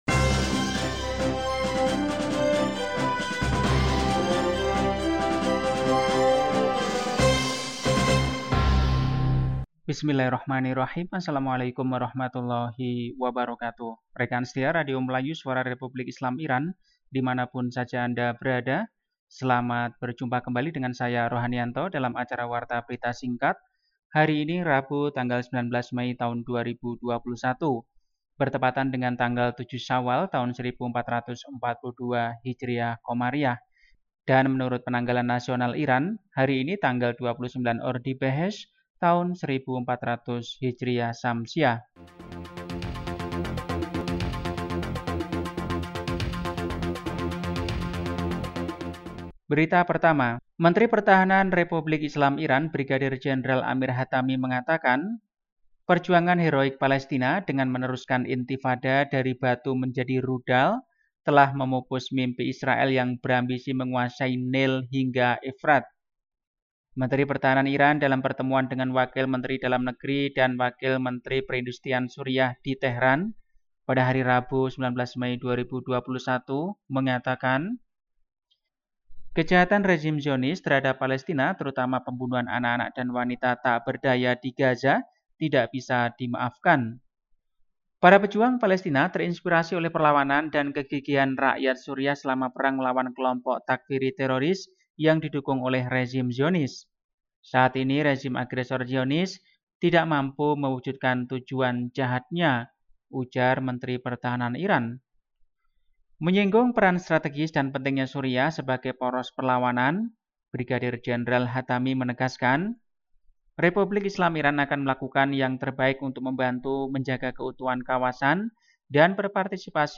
Warta berita hari ini, Rabu, 19 Mei 2021.